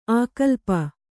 ♪ ākalpa